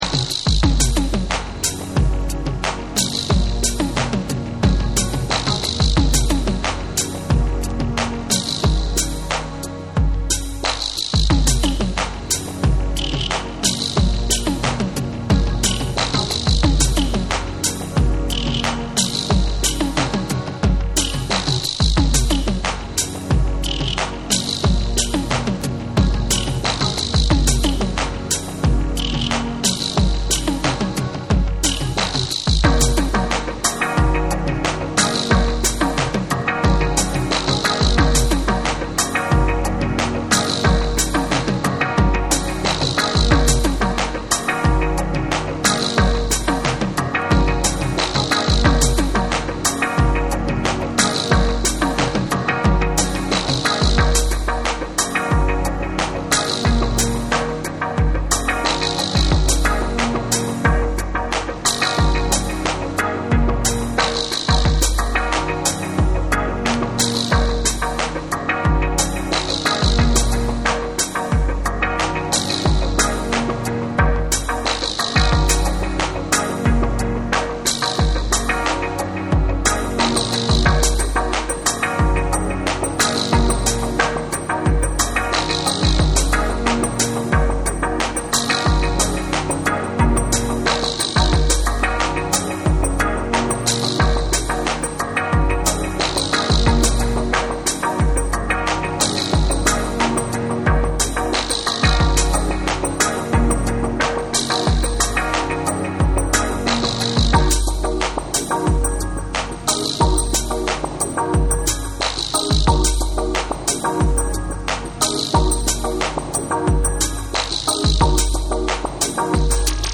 宇宙空間を連想させる壮大な世界観にハマる3(SAMPLE 3)。
BREAKBEATS / AMBIENT